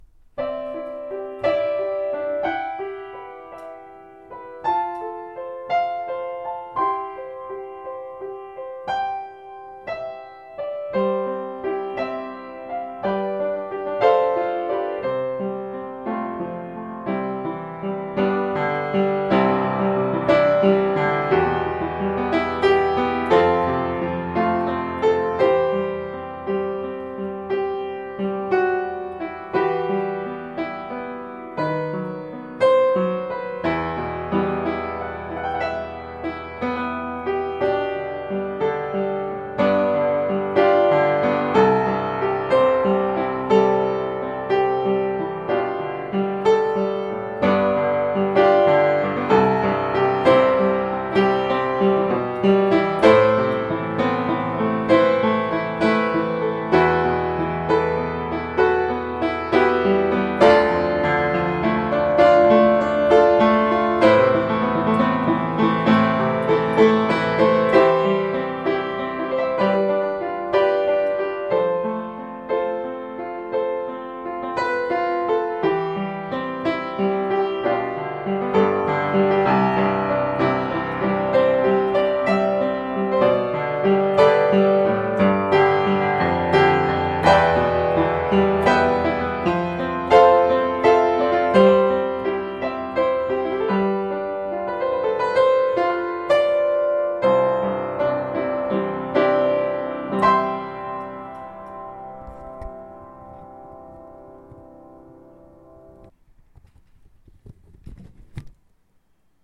Played it out of my head.
I’ve always considered it one of the most beautiful Christmas Carols.
Beautiful piano playing.